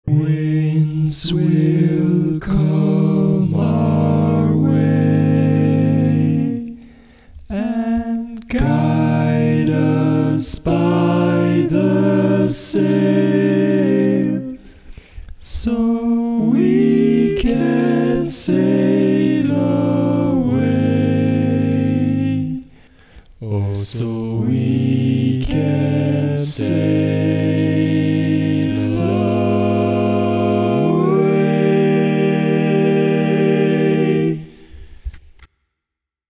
Key written in: E♭ Major
How many parts: 4
Type: Barbershop
Comments: Original tag, ballad-style barbershop
All Parts mix: